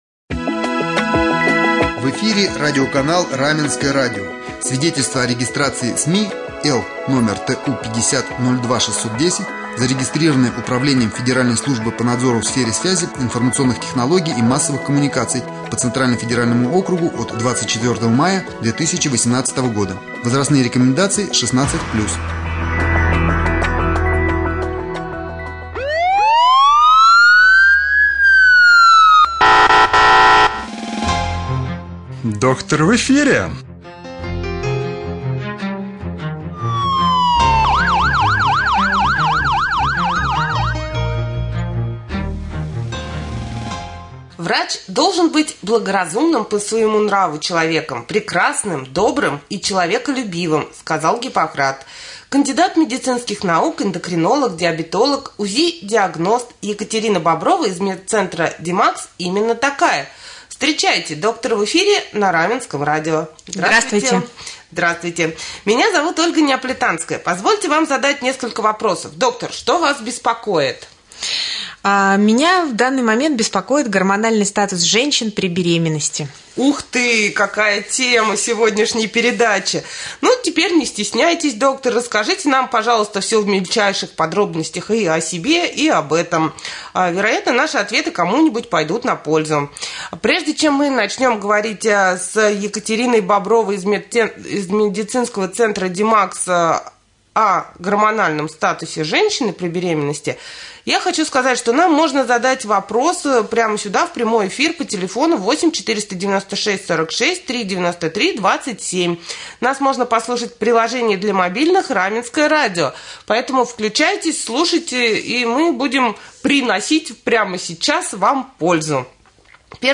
Doktor-v-jefire-27-sentjabrja-Jendokrinolog.mp3